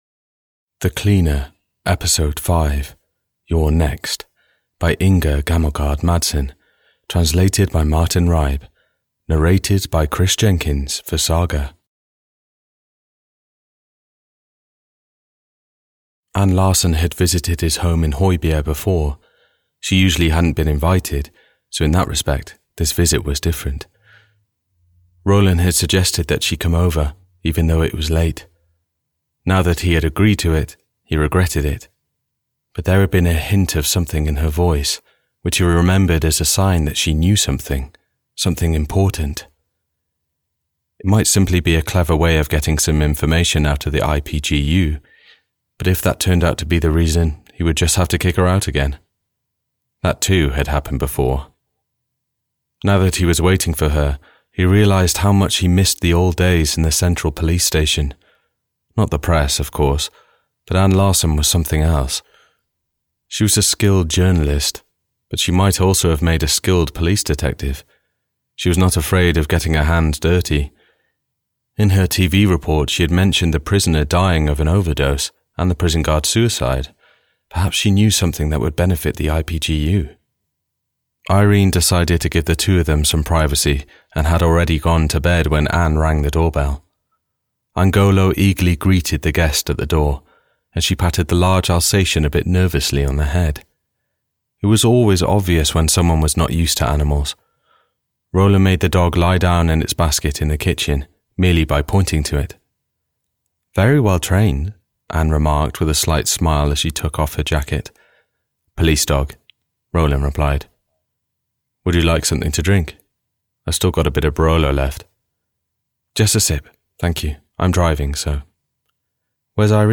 The Cleaner 5: You're Next (EN) audiokniha
Ukázka z knihy